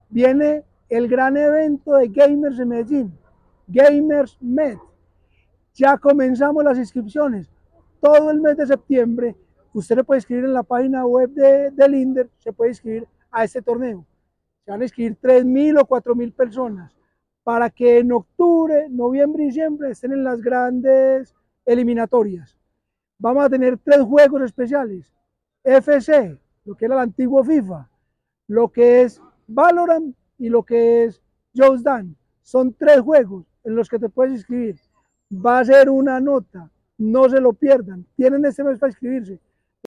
Palabras de Eduardo Silva Meluk, director de Inder